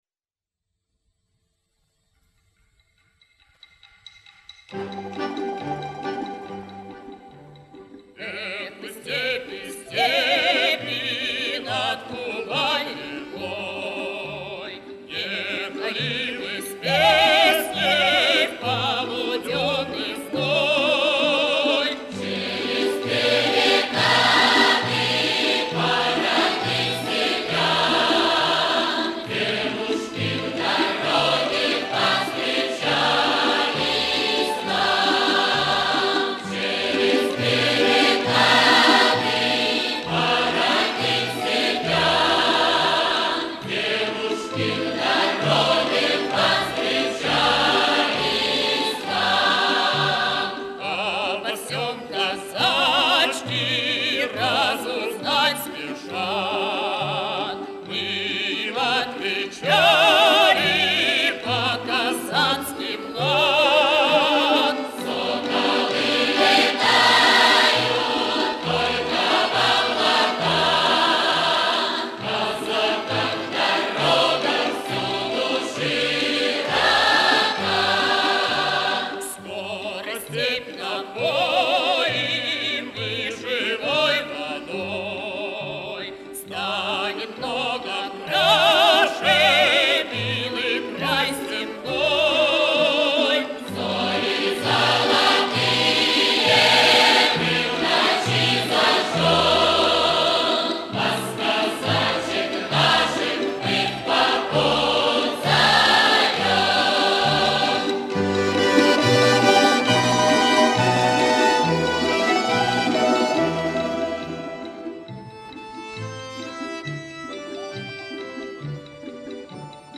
Это редкая, но очень красивая и качественная песня